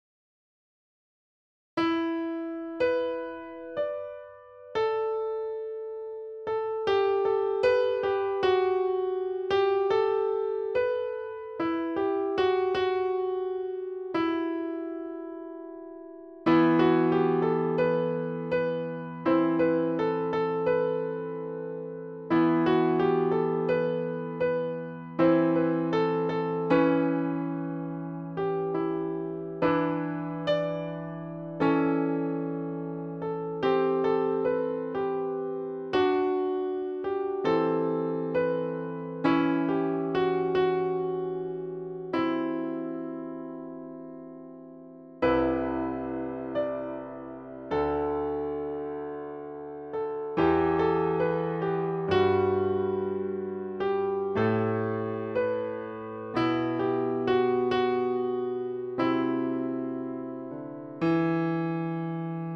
Elektrické klávesy ale nahráno jako midi